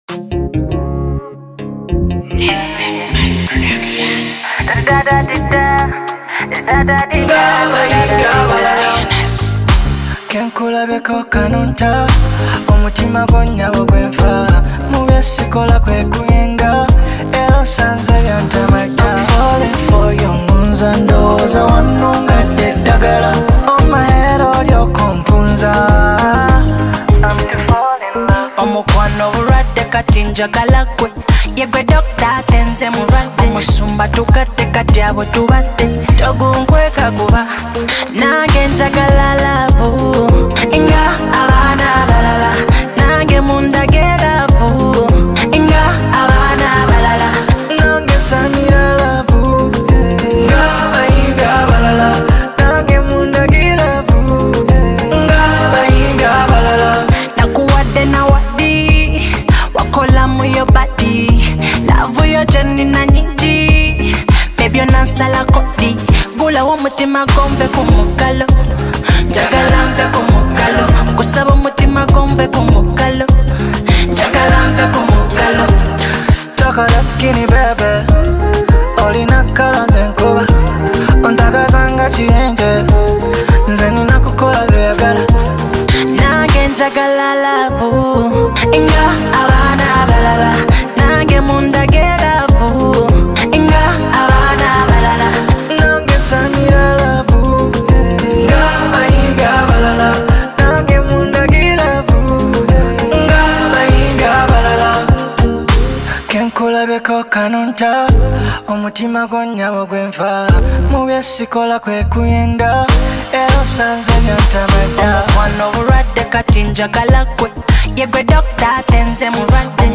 is a heartfelt Afro-Pop single released on May 30
Genre: Afrobeat